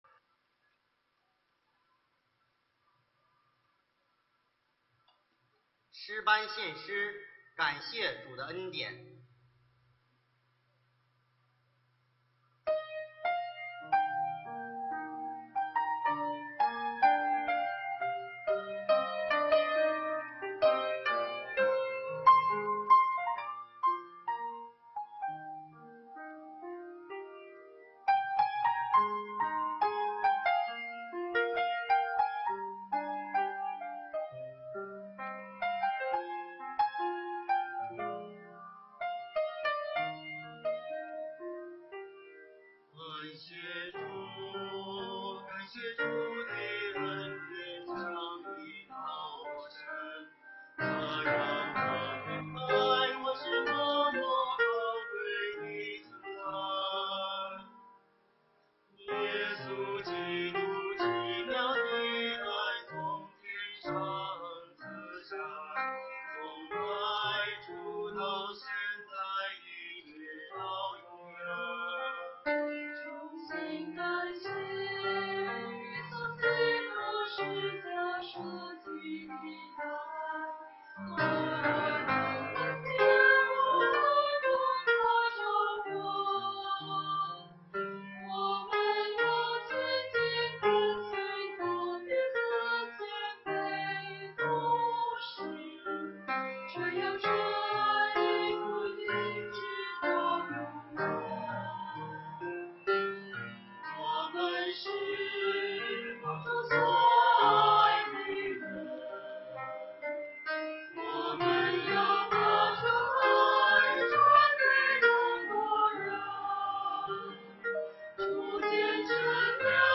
团契名称: 青年诗班
诗班献诗